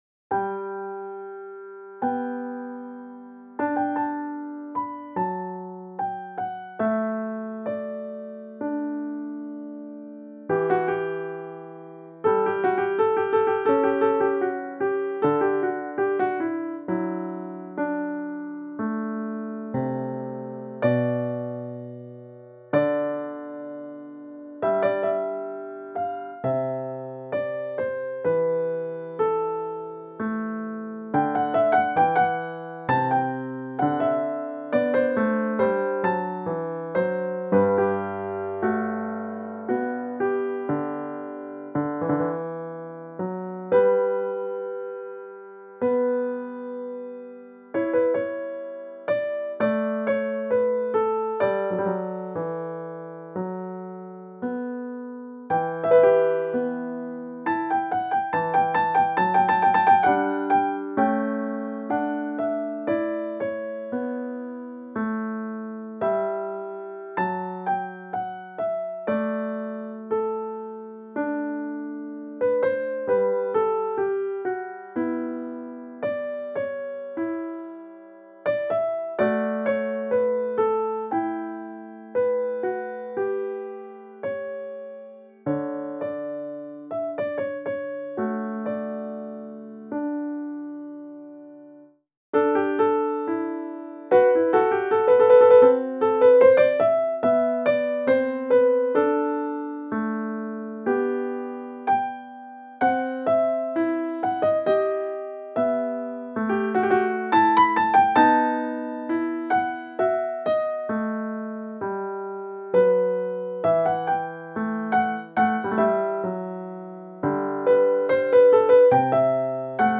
The BDMO audio files do not use any sustain pedal or reverberation. All you are hearing are pedal up samples. The dynamic range chosen was 65.
1863 Bluthner upright piano
Here is the Goldberg Aria played with a Timbral Impulse no. 9 so that it sounds like a 48" Blüthner Upright is owned by Kasimoff-Blüthner Piano Co.
demo-bluthner-1980.mp3